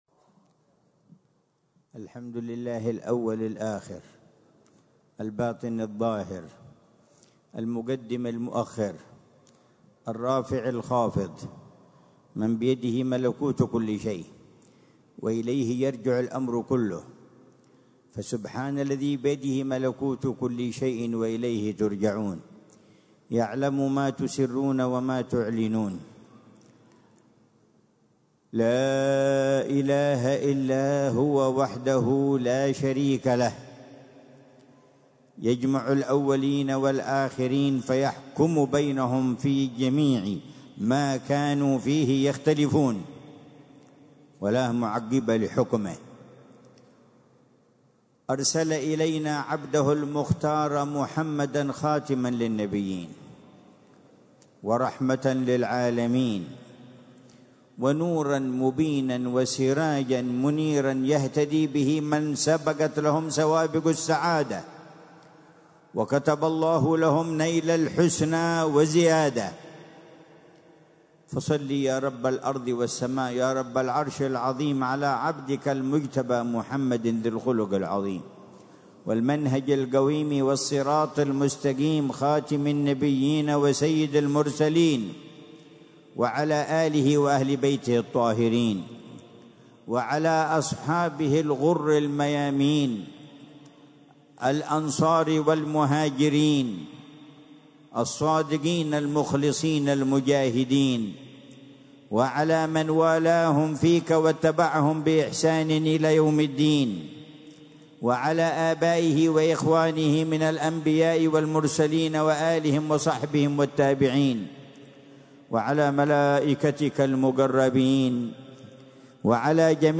محاضرة
في دار المصطفى